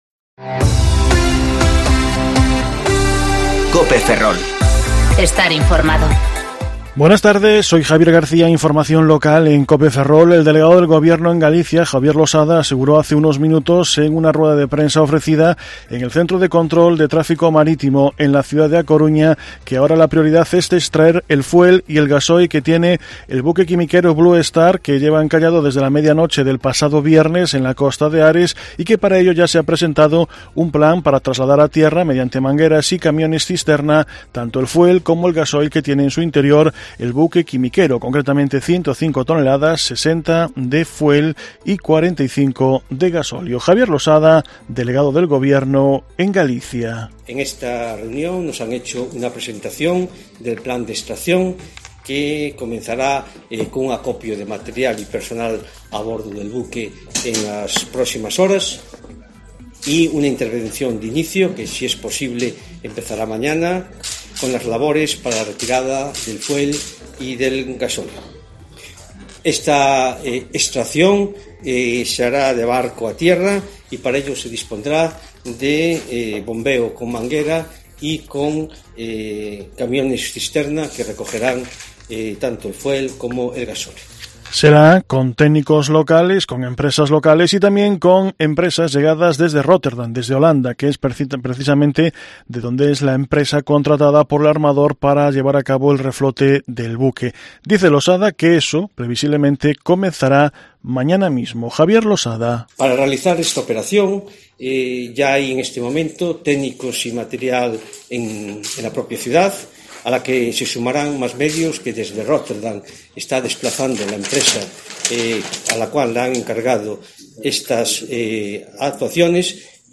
Informativo Mediodía Cope Ferrol 25/11/2019 (De 14.20 a 14.30 horas)